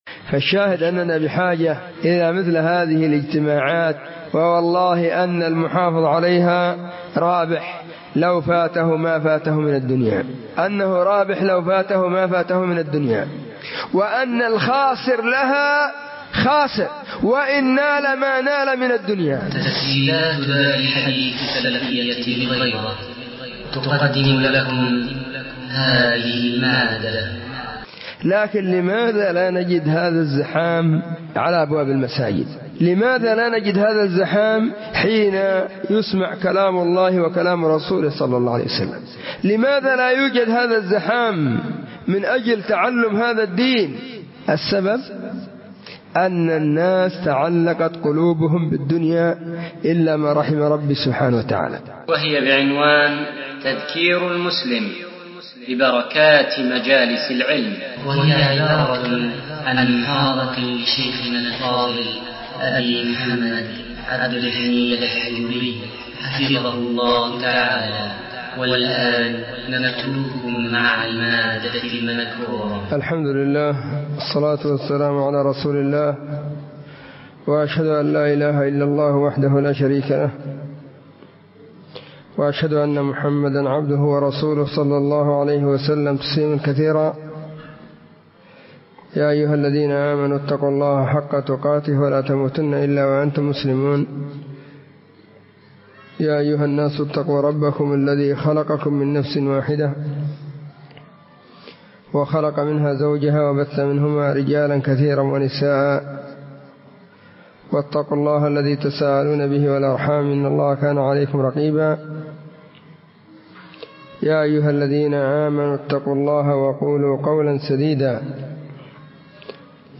📚فهذه محاضرة بعنوان*تذكير المسلم ببركات مجالس العلم*
📢 مسجد الصحابة – بالغيضة – المهرة، اليمن حرسها الله،